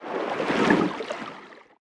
Sfx_creature_seamonkey_swim_slow_05.ogg